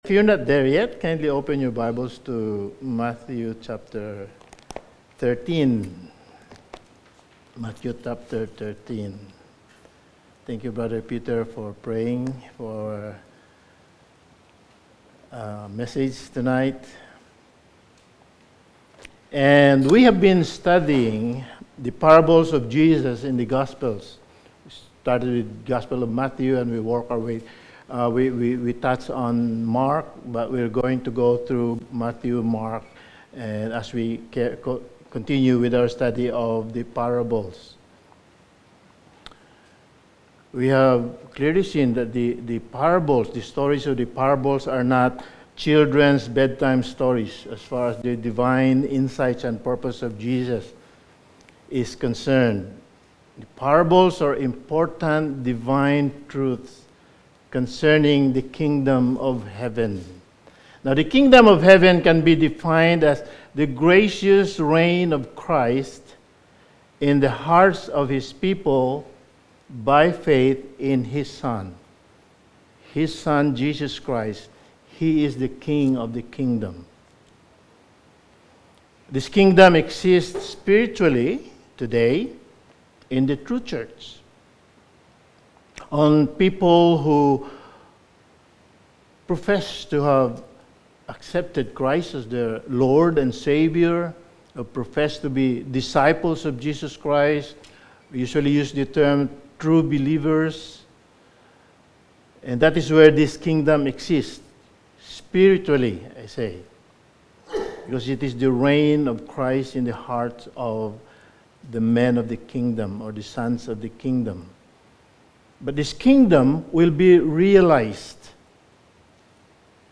Sermon
Service Type: Sunday Evening